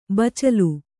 ♪ bacalu